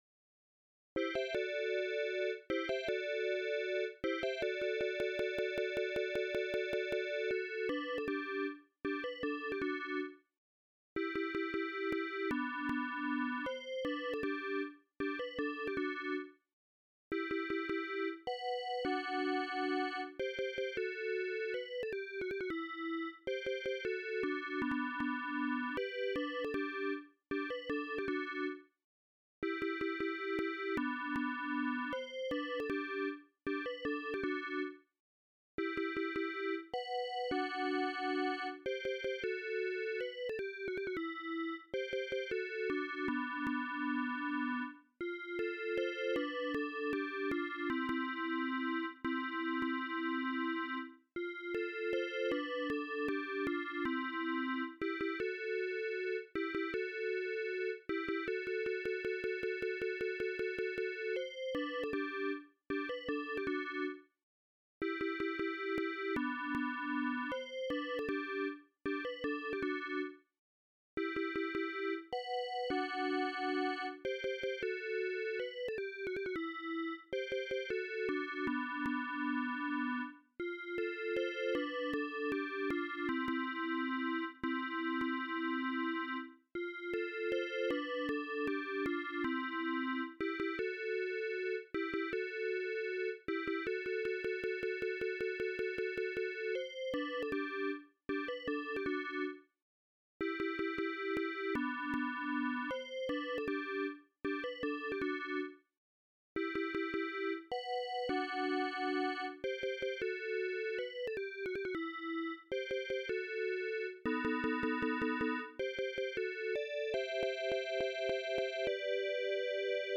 AdLib/Roland Song